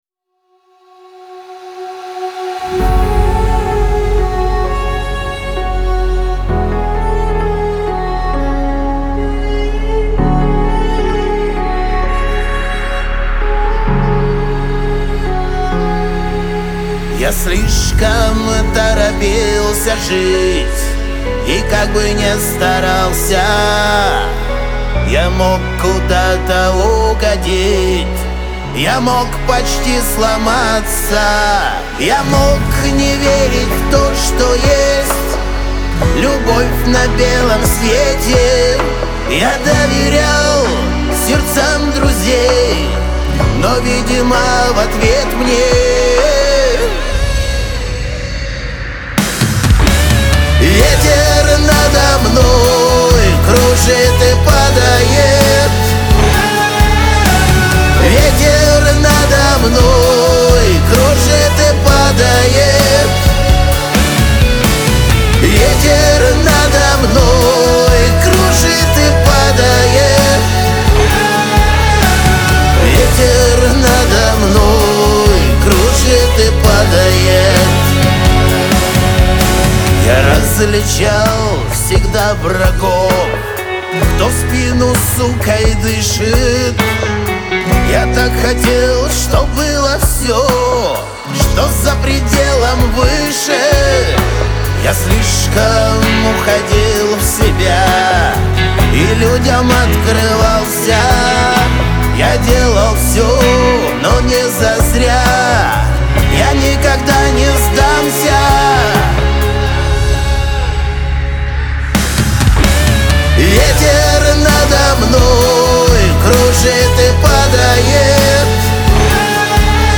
Лирика
грусть
Шансон